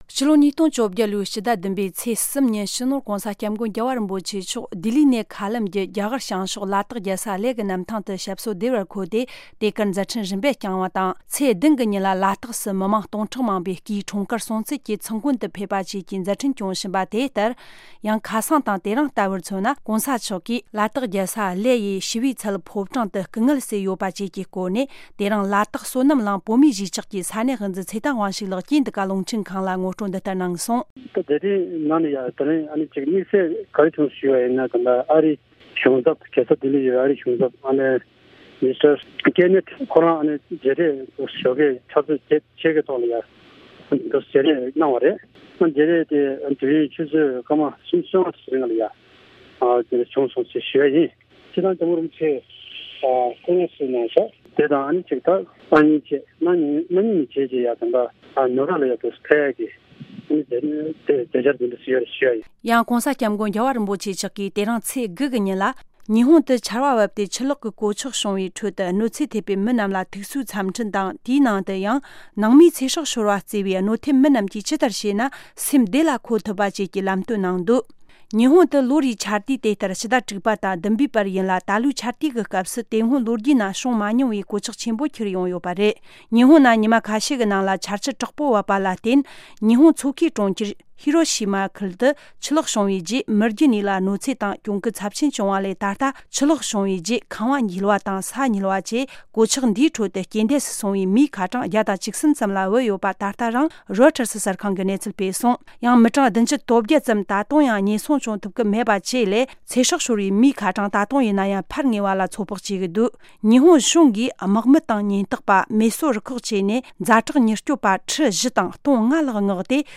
སྙན་སྒྲོན་ཞུ་ཡི་རེད།།